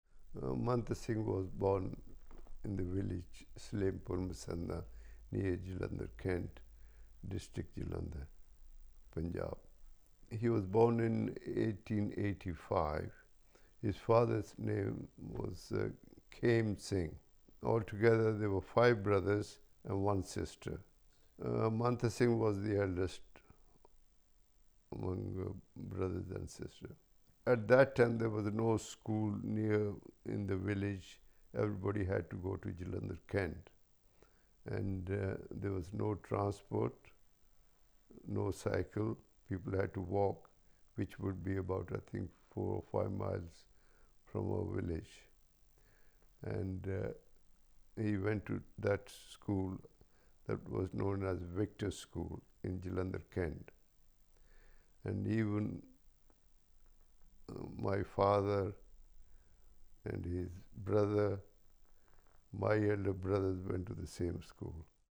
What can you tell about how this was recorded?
Location: Maidenhead, Berkshire